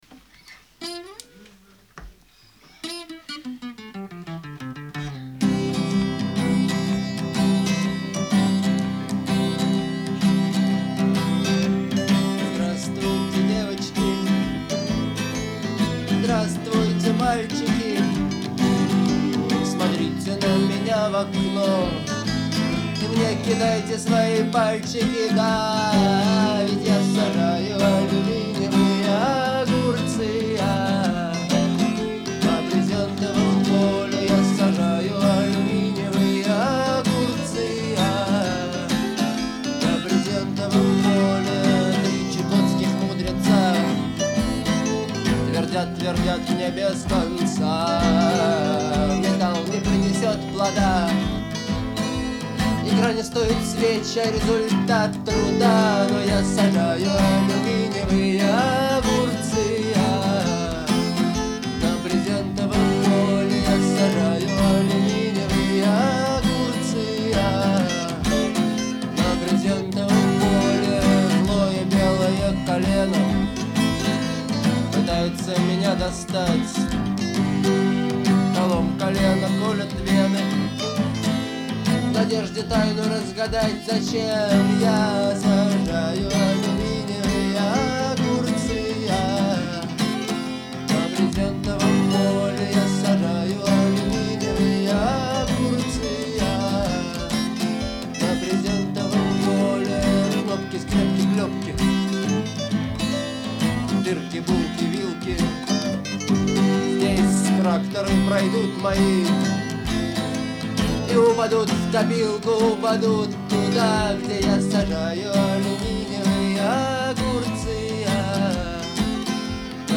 Звучание отличается простотой и запоминающейся мелодией